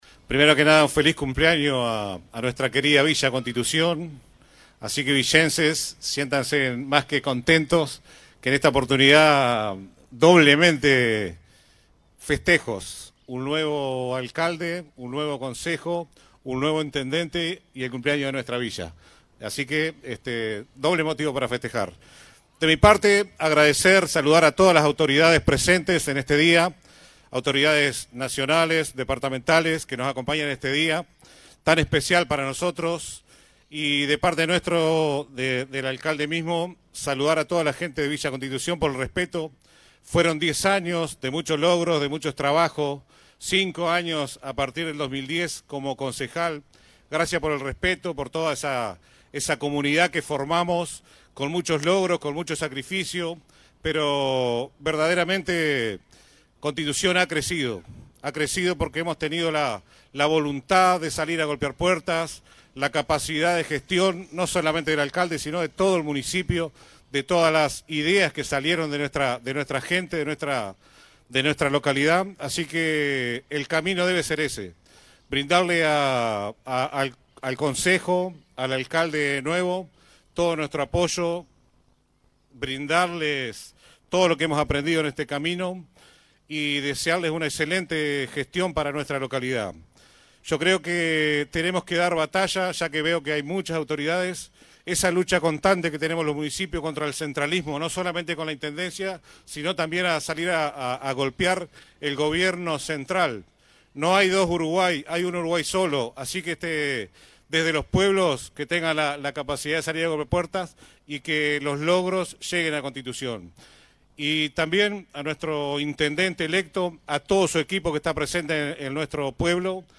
El primero en tomar la palabra fue el alcalde saliente, Carlos “Tito” Souto, quien agradeció a la población por el respeto y el apoyo recibido durante los diez años de gestión.